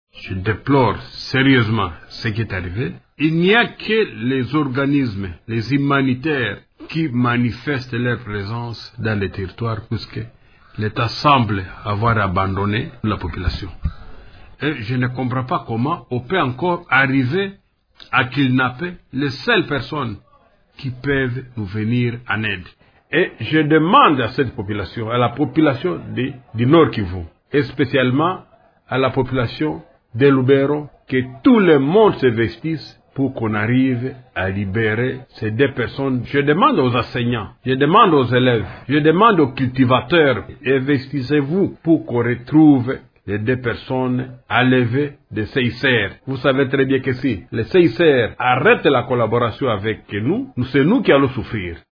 «Je demande aux cultivateurs : ‘’investissez-vous pour qu’on retrouve les deux personnes enlevées’’. Vous savez bien que si le CICR arrête la collaboration avec nous, c’est nous qui allons souffrir», a déclaré lundi au cours d’une conférence de presse Venant Tshipasa, doyen des élus de Lubero.